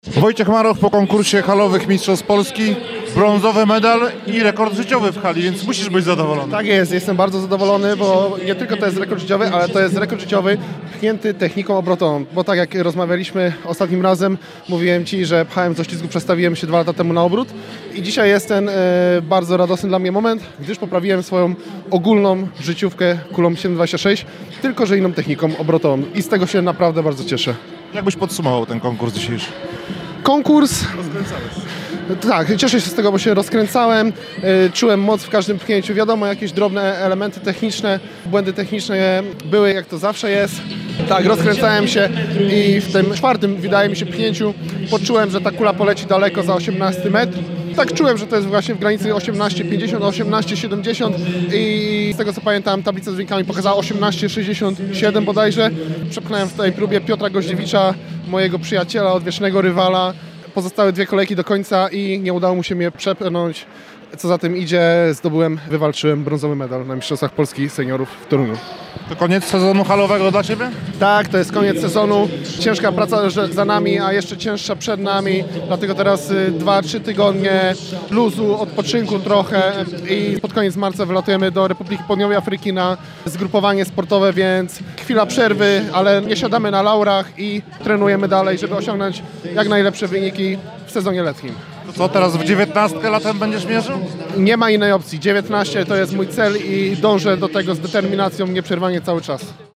– mówił naszemu reporterowi.